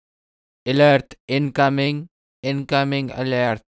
Alert incoming
Tap and play instantly — free meme sound on Sound Buttons Hub.
alert-incoming.mp3